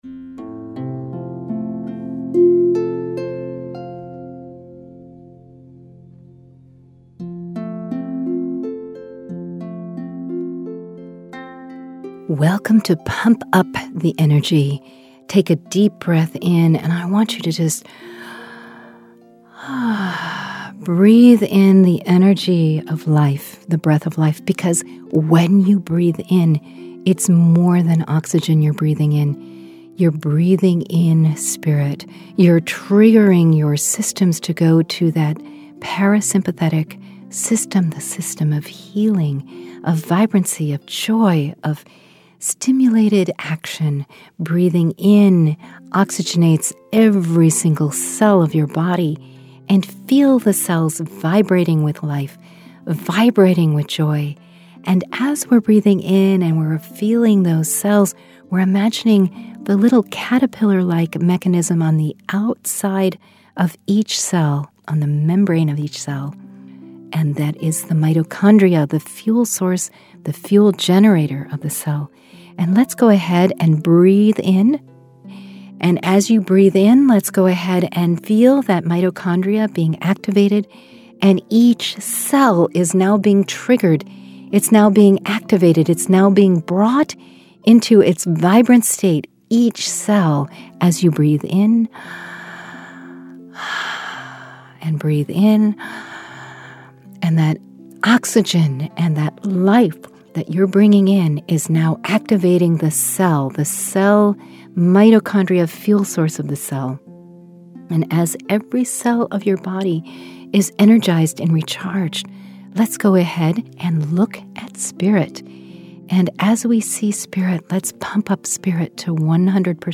3 Q5 Meditations: